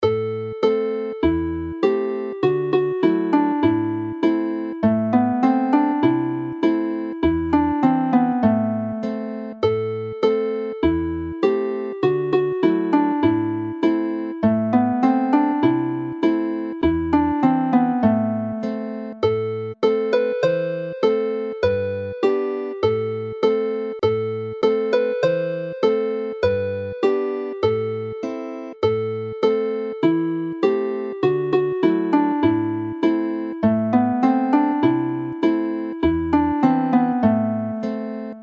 The song was included in Dm in its own monthly set, one of the earliest in this collection but is easier for male voices to sing in the key presented here, Am.
Play the melody slowly